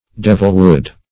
Devilwood \Dev"il*wood`\, n. (Bot.)